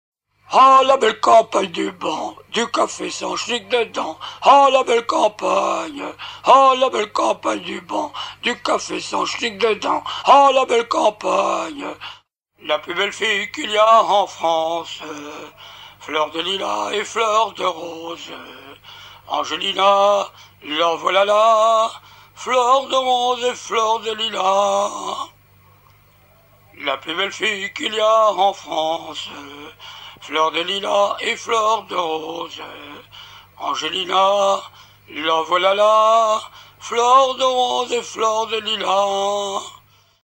pêche morutiers
à hisser main sur main
circonstance : maritimes
Chansons maritimes